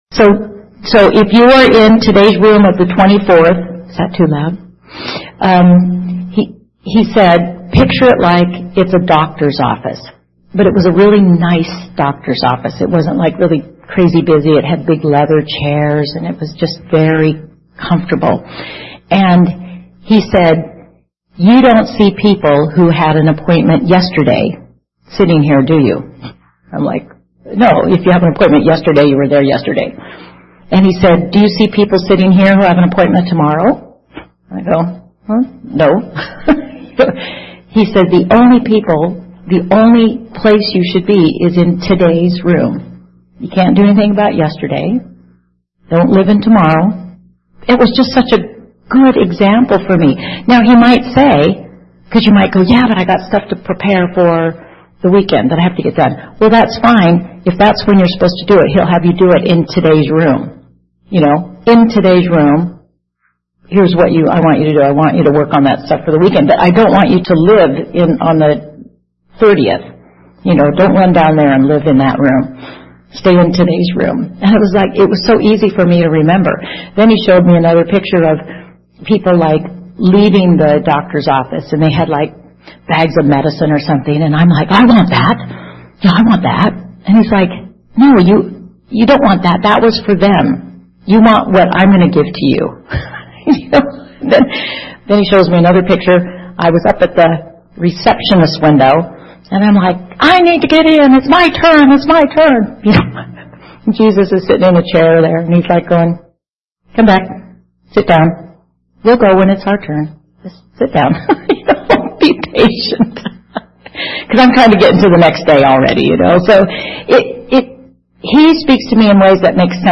TAPC - Retreat Recording